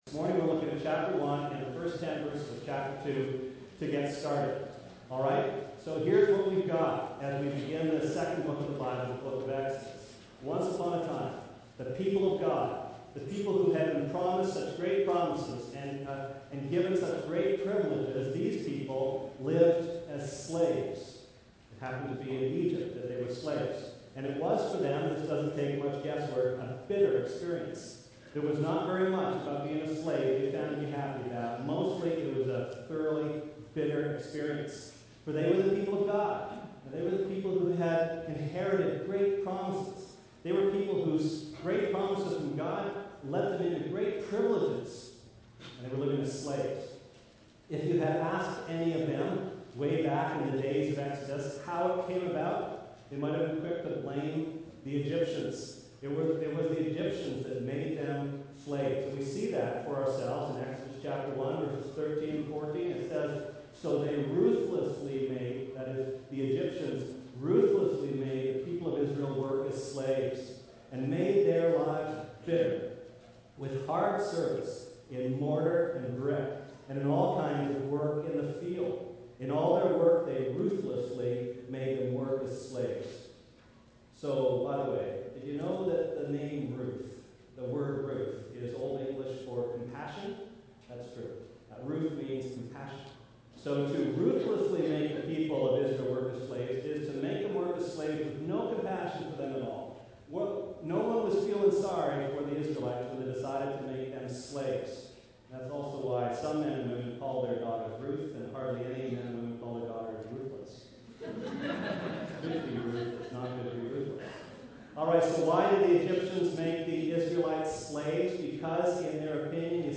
Sermon Archives September 13 2009